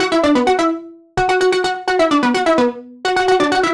Index of /musicradar/french-house-chillout-samples/128bpm/Instruments
FHC_Arp A_128-E.wav